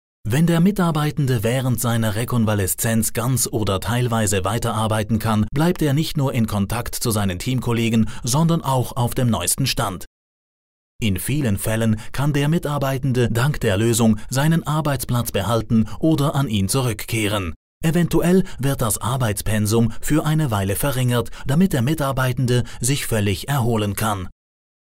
Professionelle Sprecher und Sprecherinnen
Schweizerisch
Männlich